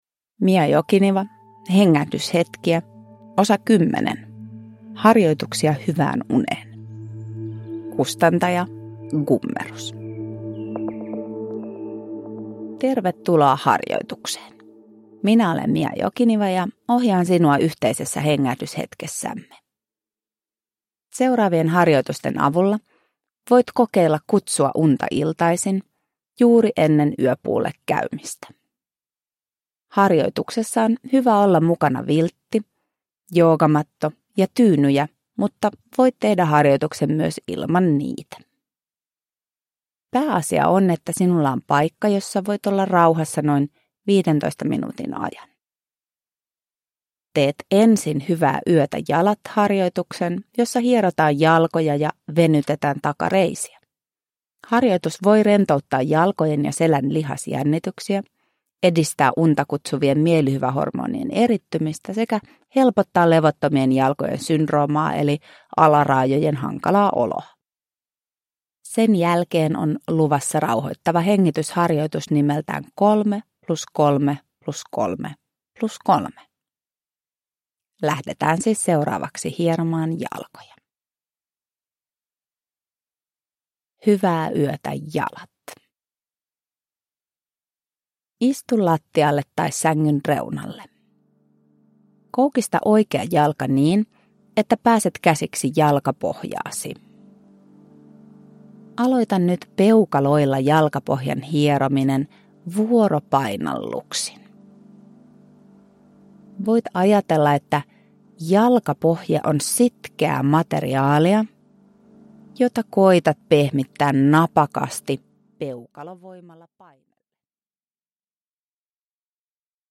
Hengähdyshetkiä (ljudbok